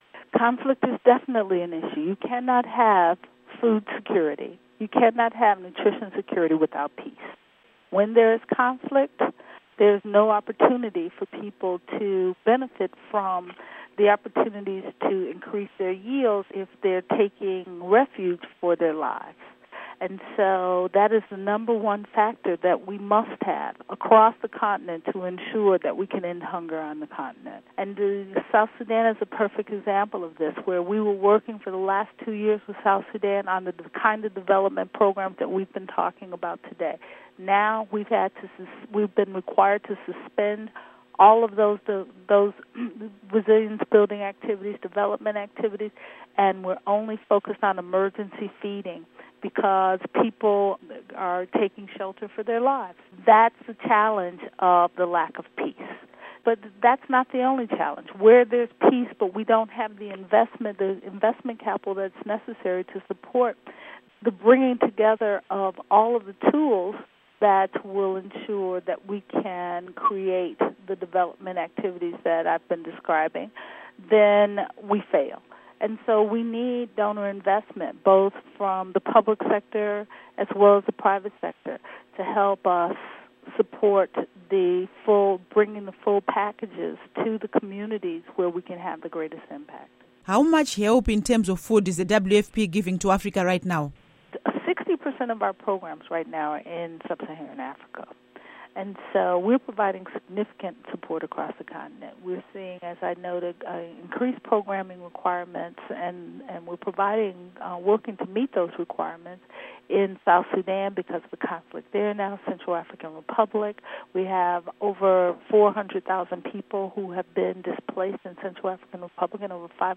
Interview With WFP Director Ertharin Cousin